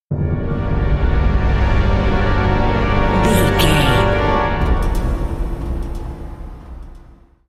Thriller
Aeolian/Minor
synthesiser
percussion
ominous
dark
suspense
haunting
creepy